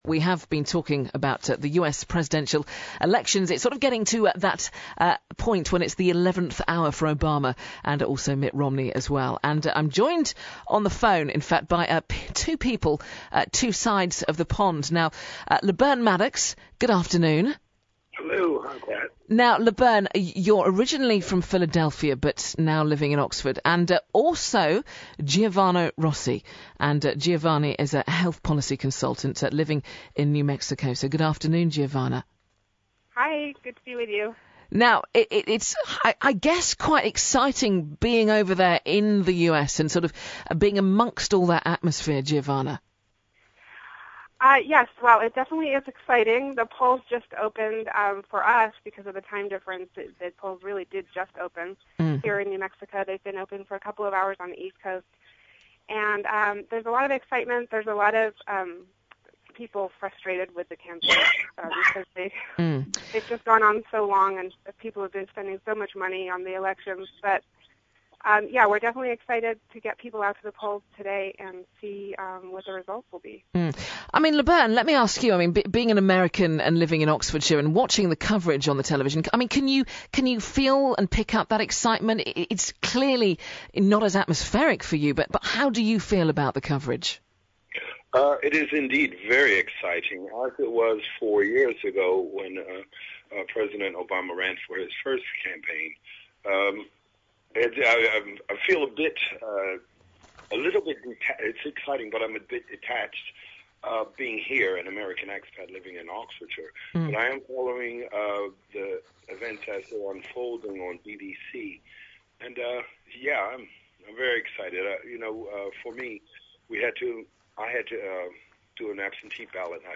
USA-ELECTION-BBC-OXFORD-.mp3